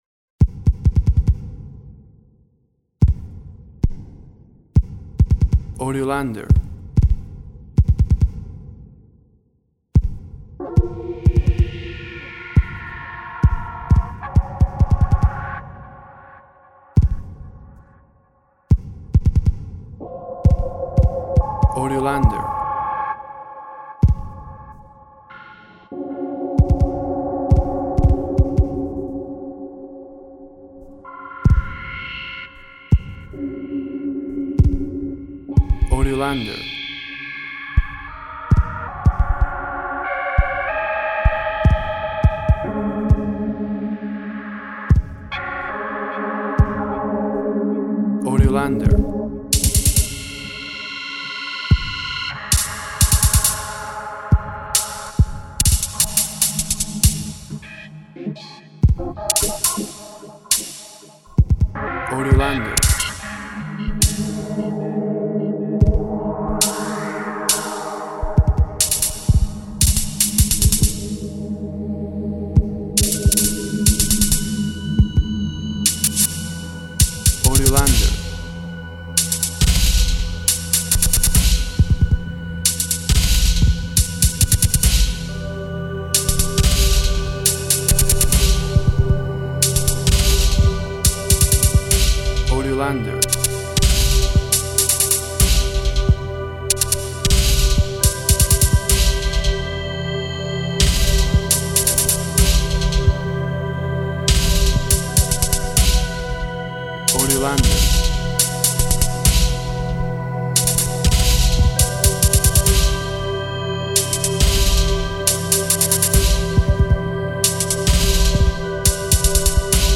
WAV Sample Rate 16-Bit Stereo, 44.1 kHz
Tempo (BPM) Indefinite